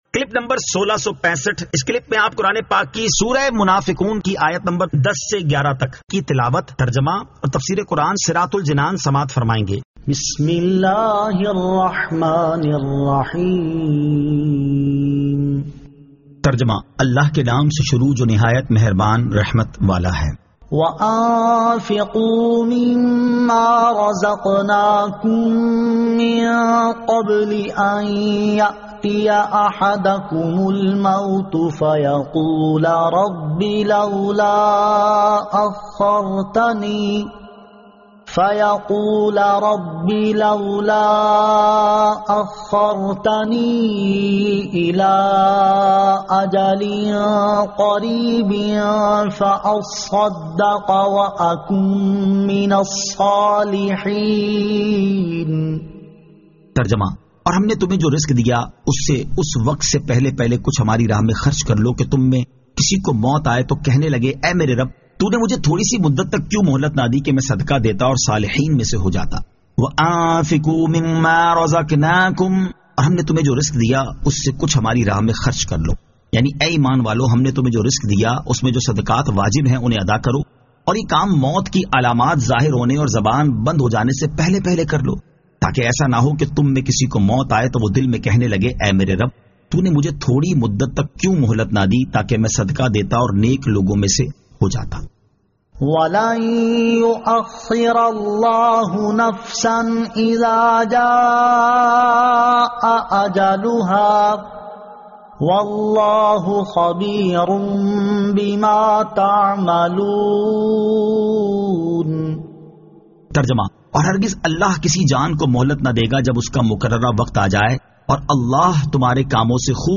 Surah Al-Munafiqun 10 To 11 Tilawat , Tarjama , Tafseer